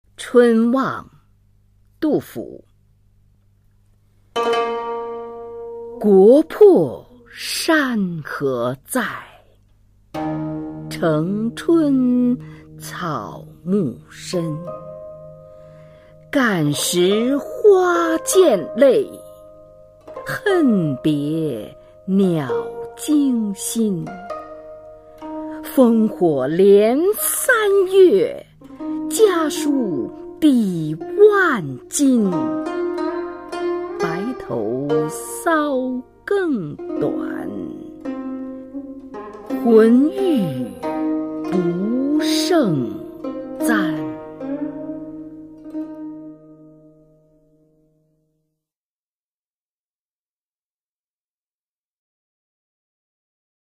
[隋唐诗词诵读]杜甫-春望（女） 唐诗朗诵